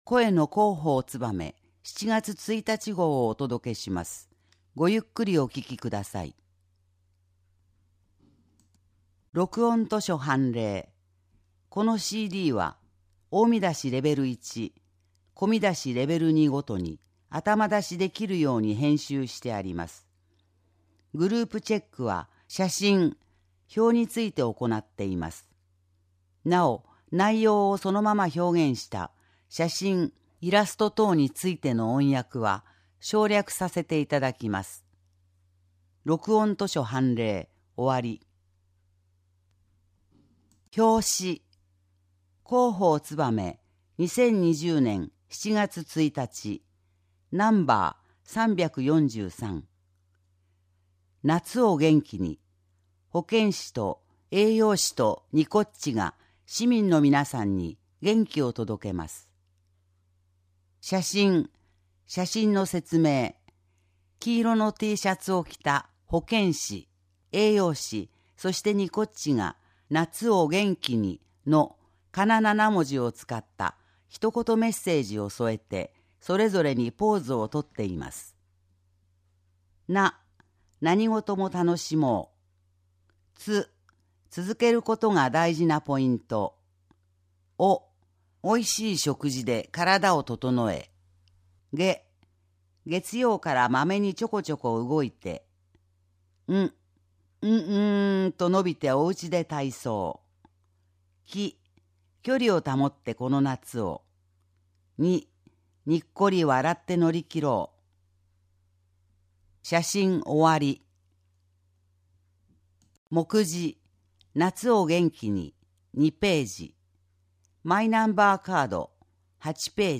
こちらではMP3版の声の広報を、項目ごとに分けて配信しています。
1.広報つばめ7月1日号・録音図書凡例・表紙・もくじ（3分14秒） (音声ファイル: 3.0MB)